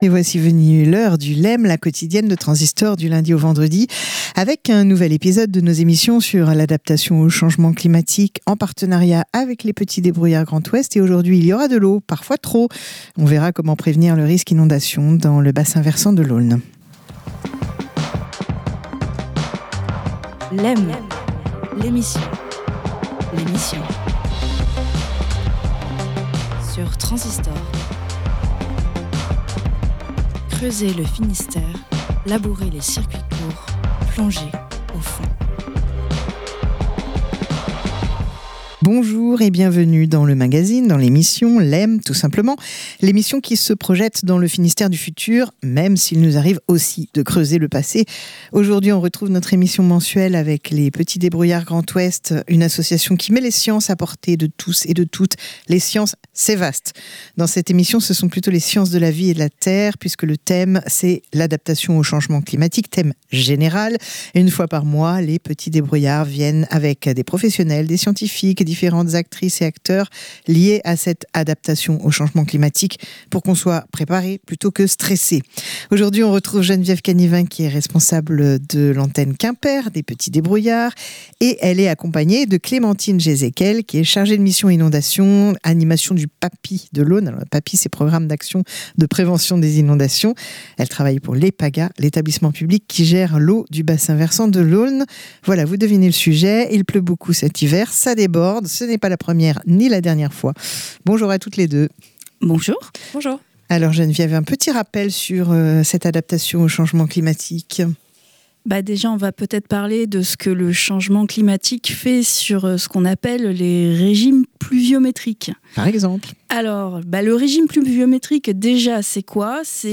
Écoutez la série radio sur l'adaptation au changement climatique soutenue par l'UE